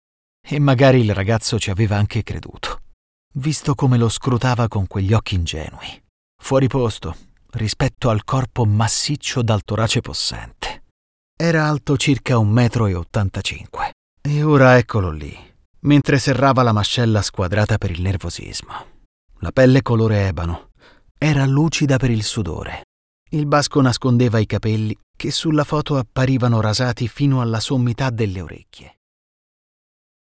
Male
Bright, Engaging, Friendly, Versatile, Authoritative, Character
My voice is bright, energetic, sensual, warm, enveloping, suitable for dubbing and radio.
Main Reel.mp3
Microphone: Neumann TLM 103, Universal Audio Sphere Dlx